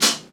• Thin Hat Sound Sample D Key 28.wav
Royality free high-hat tuned to the D note. Loudest frequency: 4801Hz
thin-hat-sound-sample-d-key-28-oWE.wav